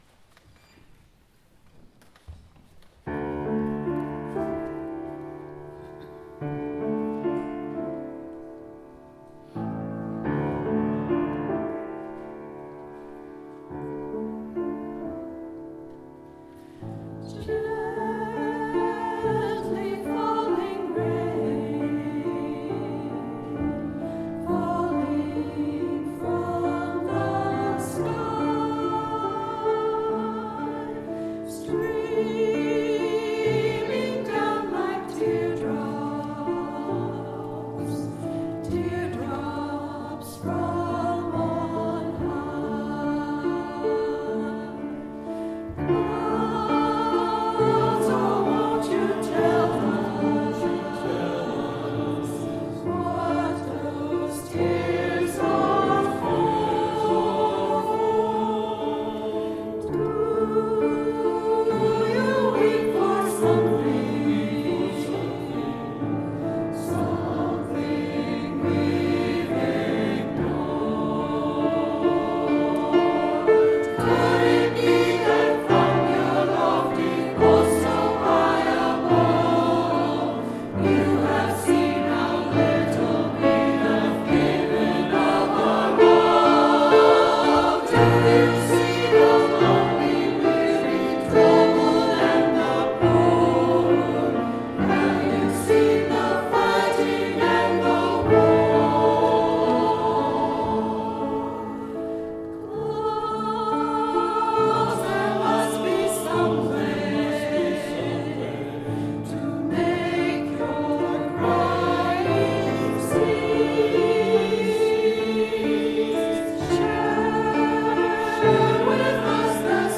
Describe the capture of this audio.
[Fuusm-l] Some music from past services